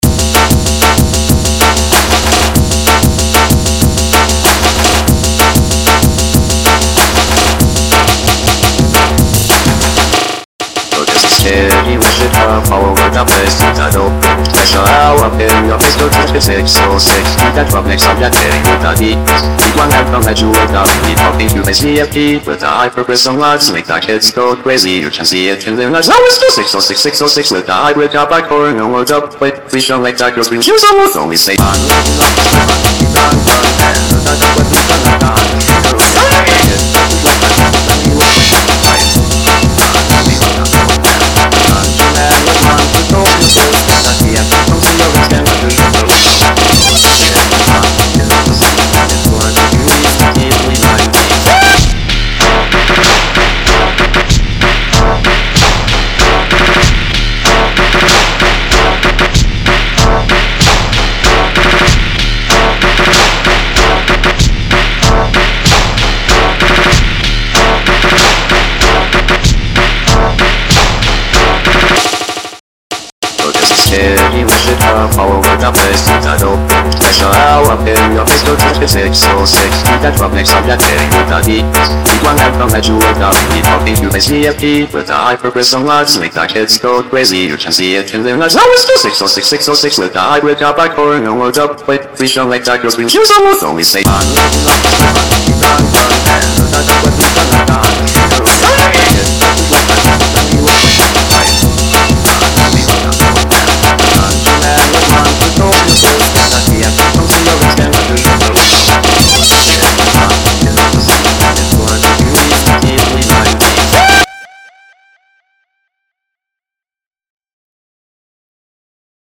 Music / Techno